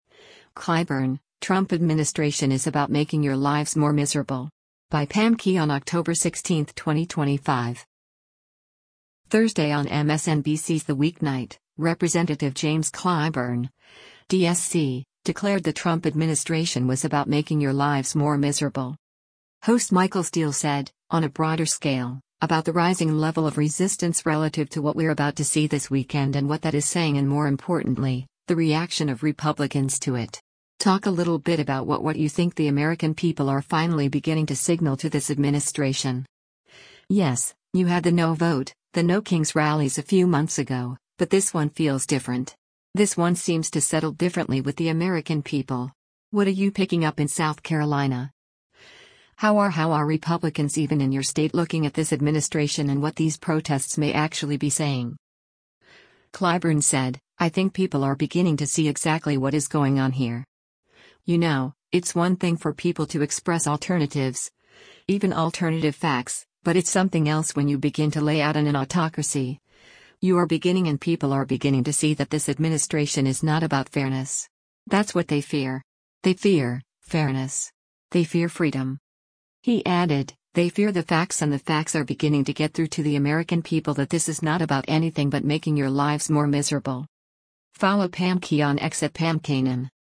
Thursday on MSNBC’s “The Weeknight,” Rep. James Clyburn (D-SC) declared the Trump administration was about “making your lives more miserable.”